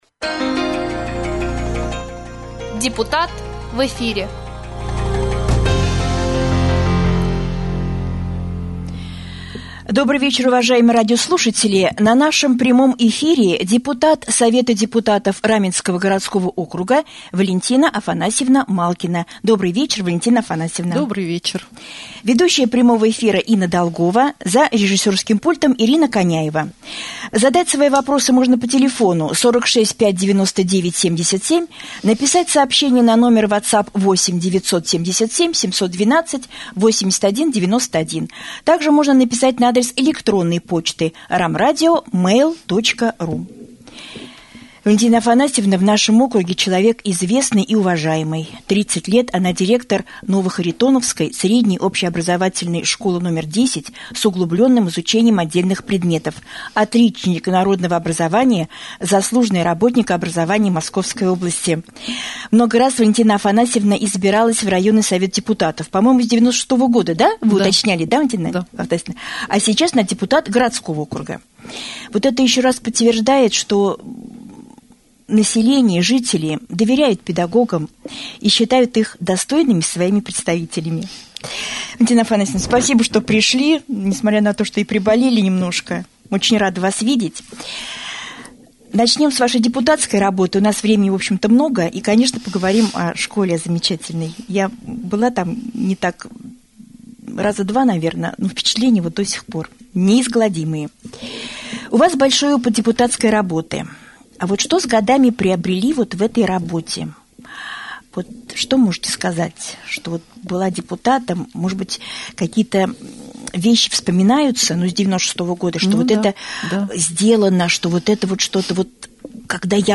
Депутат Совета депутатов Раменского городского округа Валентина Афанасьевна Малкина стала гостем прямого эфира на Раменском радио.